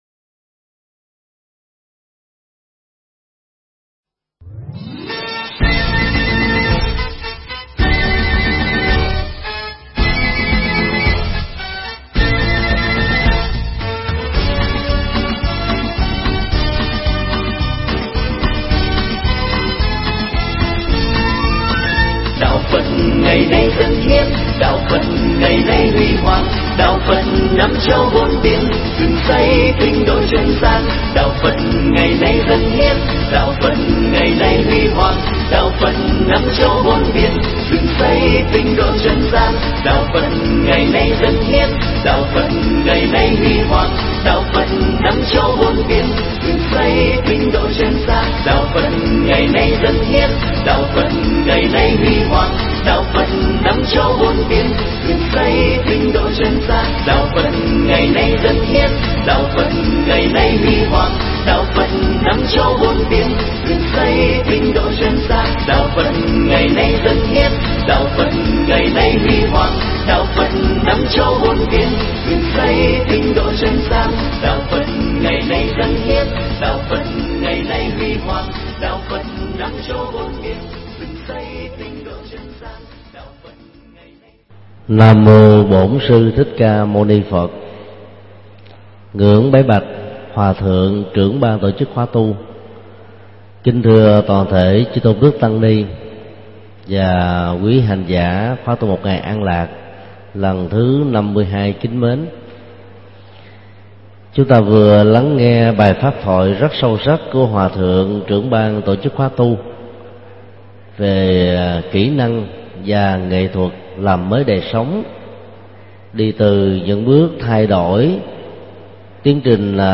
Nghe mp3 pháp thoại Cuộc Đời Chẳng Đẹp Sao do thầy Thích Nhật Từ giảng tại Chùa Phổ Quang, ngày 28 tháng 12 năm 2008.